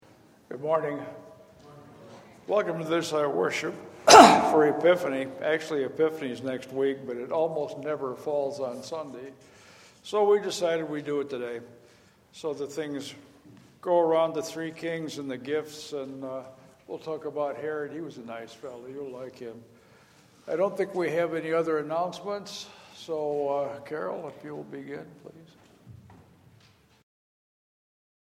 02 Welcome and Announcements.mp3